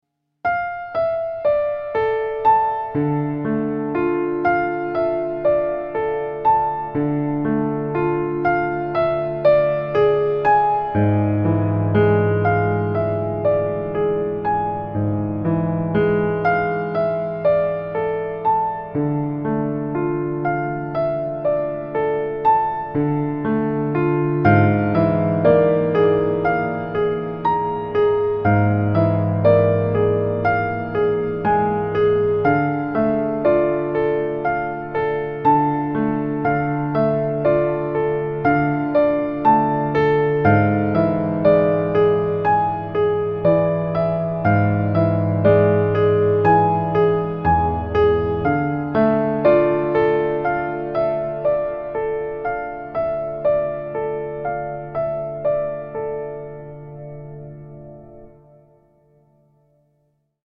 Genres: Relaxing Music
Tempo: 120 bpm